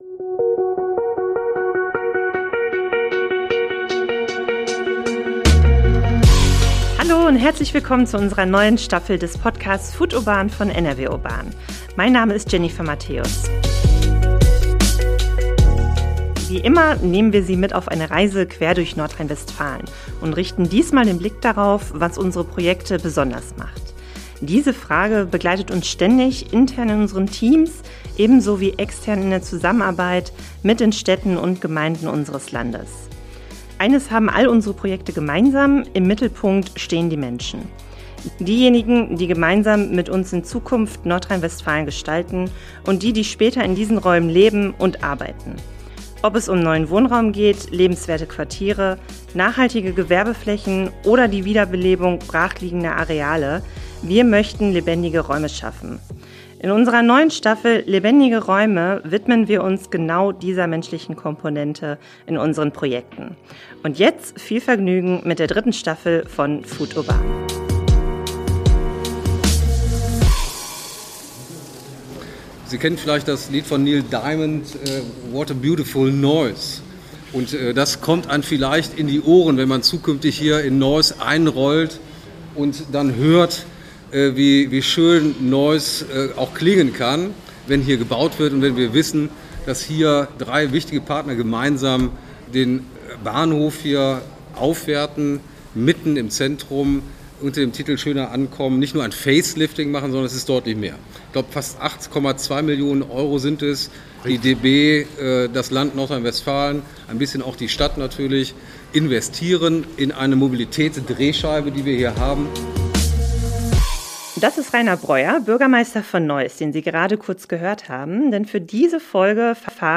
Ergänzt durch O‑Töne aus der Pressekonferenz mit Bürgermeister Reiner Breuer und Bauministerin Ina Scharrenbach entsteht ein lebendiger Einblick in die Bedeutung von Bahnhöfen als öffentliche Räume, ihre Geschichte, ihre Zukunft – und die besonderen Chancen für Neuss.